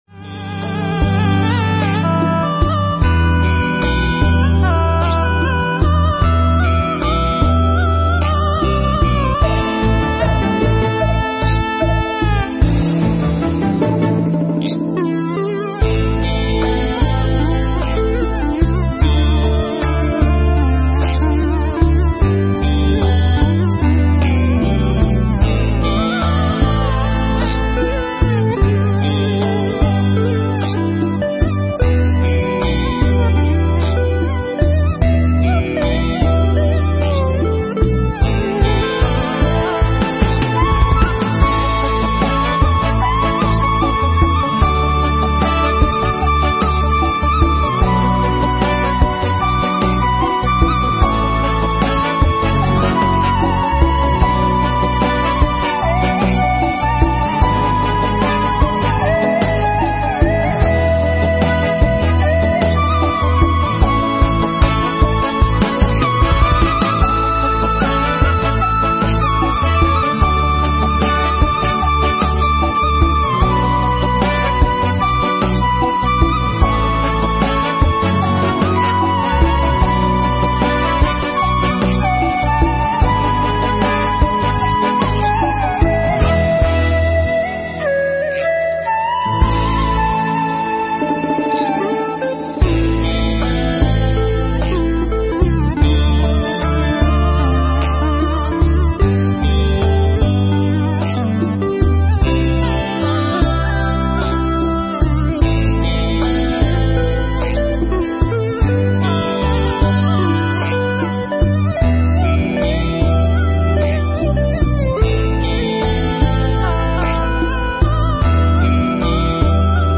Mp3 Pháp Thoại Sự Kỳ Diệu Của Não Phải Phần 2 – Tĩnh Lặng Và An Lạc
trong khóa tu một ngày an lạc lần 86 tại Tu Viện Tường Vân